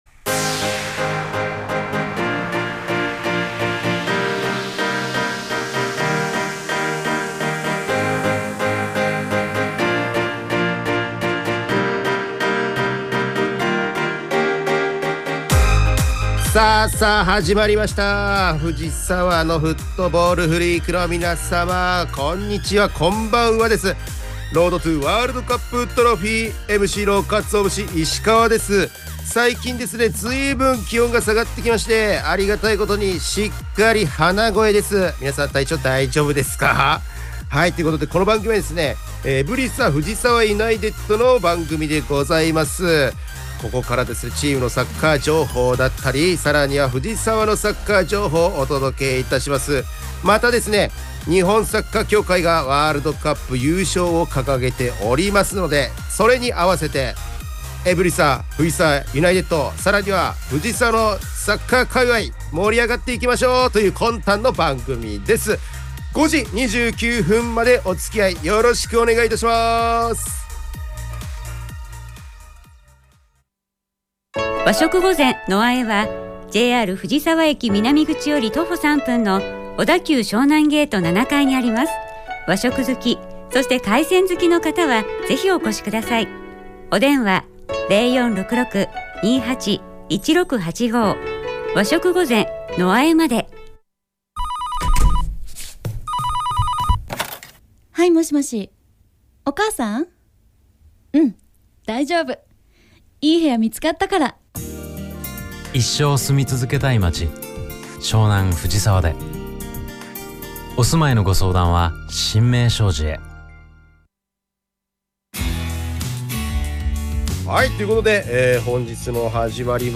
エブリサ藤沢ユナイテッドが提供する藤沢サッカー専門ラジオ番組『Road to WC Trophy』の第2期の第34回放送が11月22日(金)17時に行われました☆